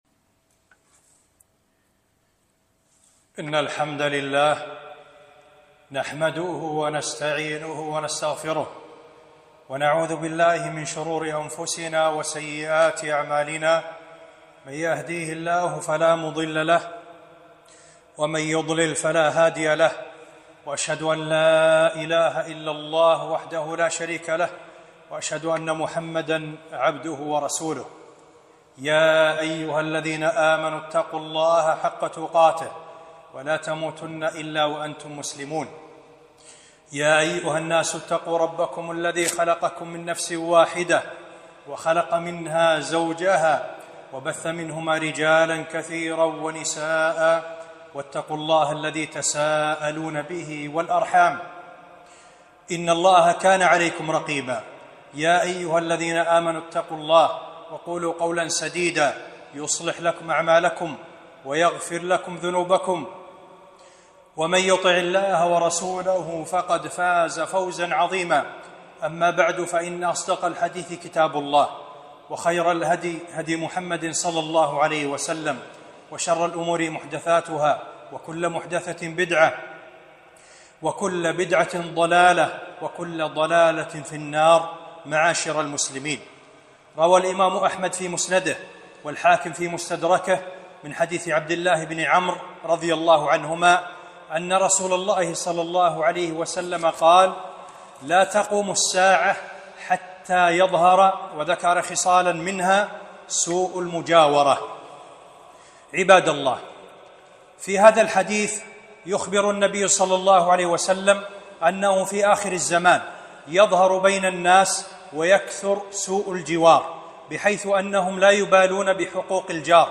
خطبة - حقوق الجار